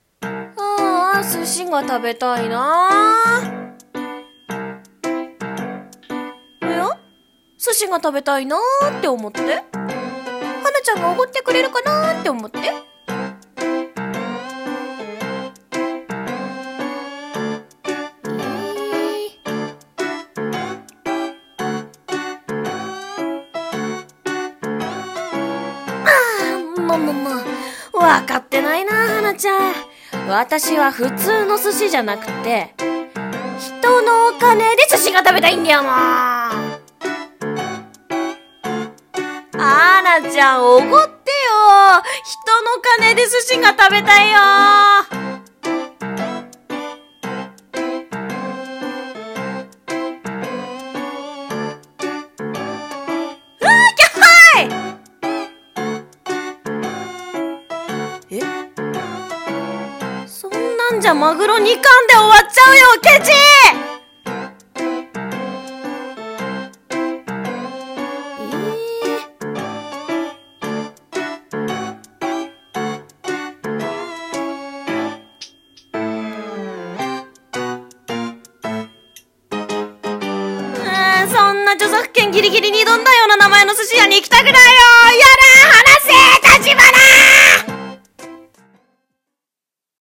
声劇】天才作家と生真面目な寿司